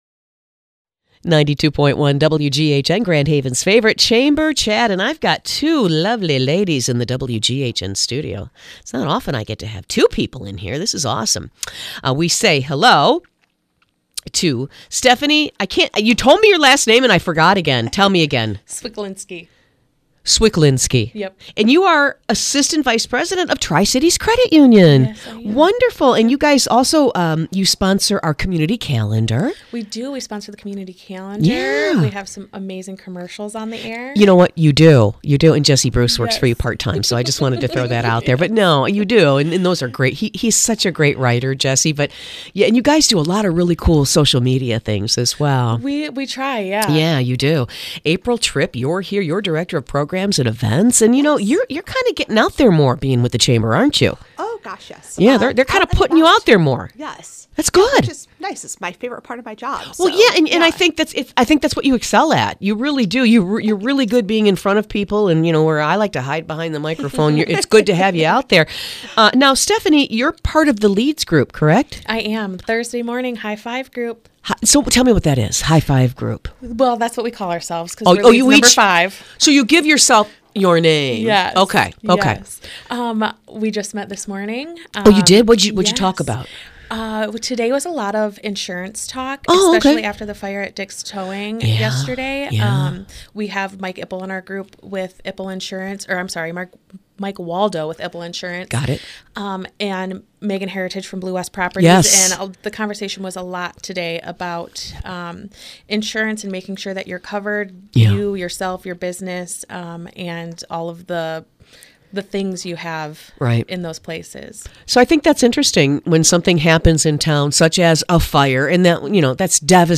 Hear all about LEADS and the chamber in this fun interview.